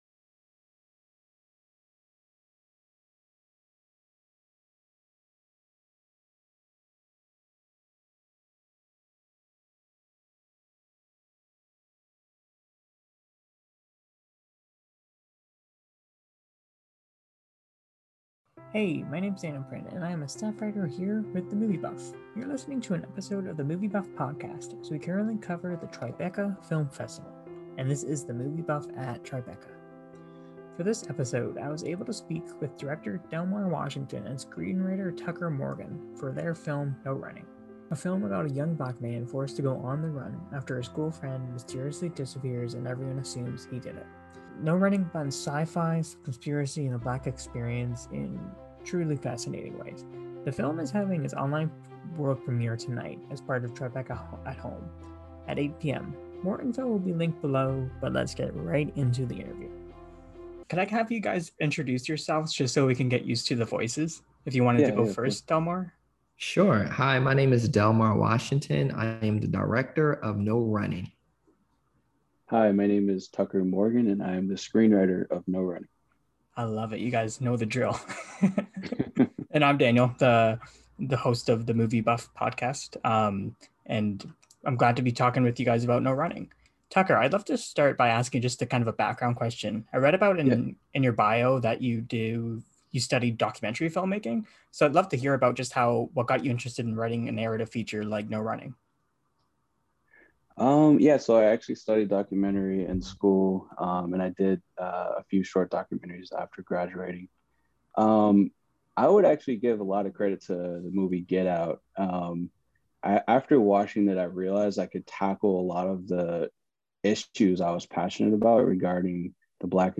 Tribeca 2021 Interview